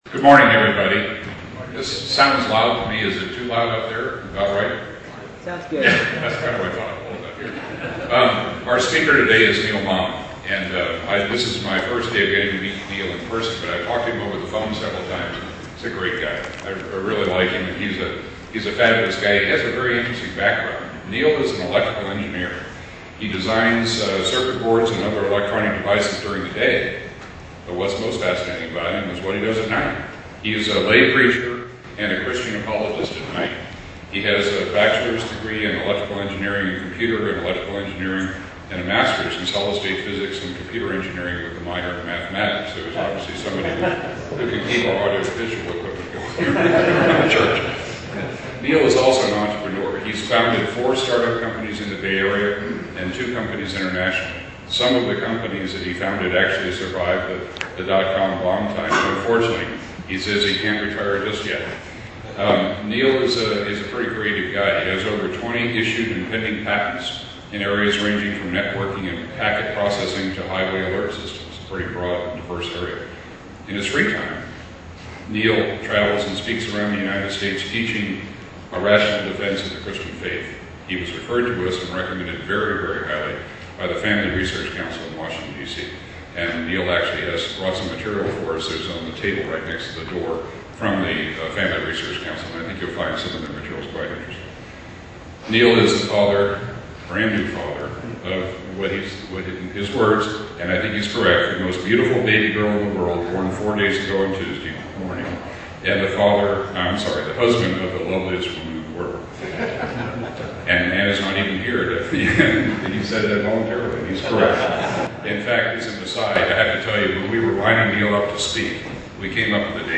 GodAndCountryHillsideCovenantChurch1-20-07.mp3